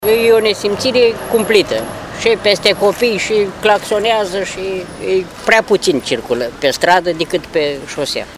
Un anticar care vinde cărți în zonă a ajuns la capătul răbdărilor.
anticar.mp3